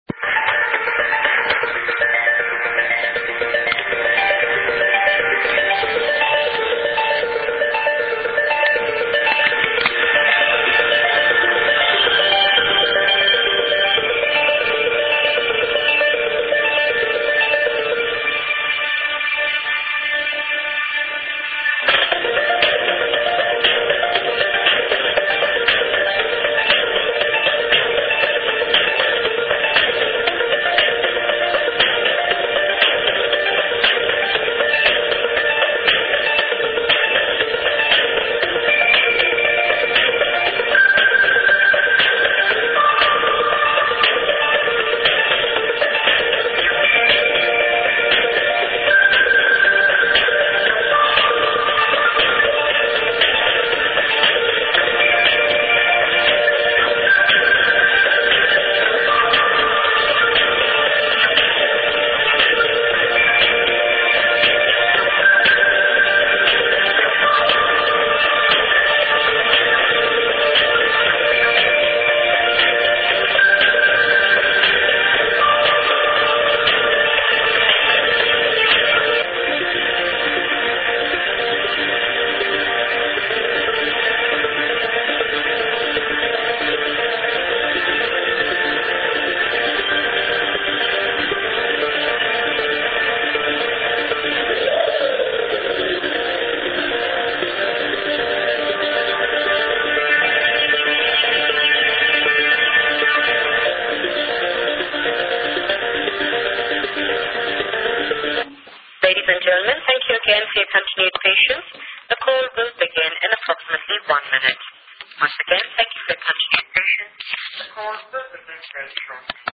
unknown electronic track
hi... can anyone please ID this track for me? sorry for a bad low quality (it's real shit, I recorded it on a conference call, the operator set this melody as the standby sound before the call started)... seems like a mixture of electronic (the beat is broken) and chillout/ambient... in fact it sounds pretty much like a production of Enigma, or any other stuff like that... but definitely it's not trance...